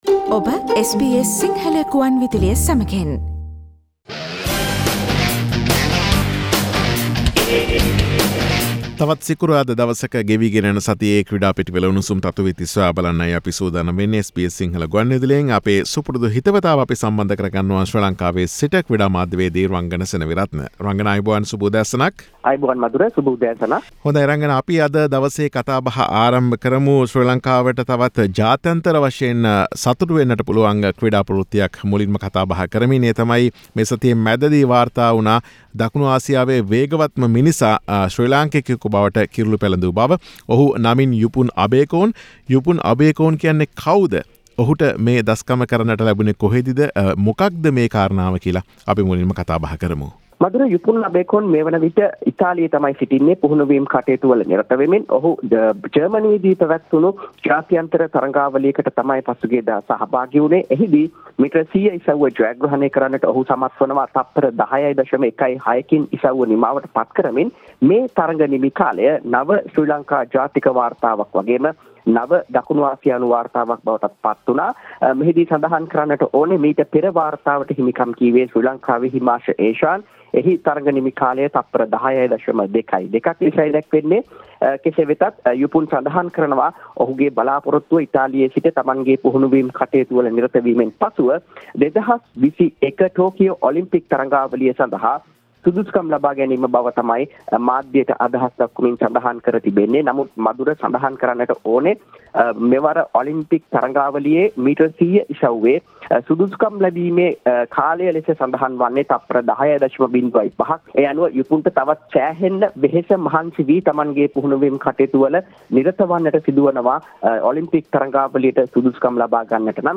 SBS Sinhalese Sports Wrap with Sports Journalist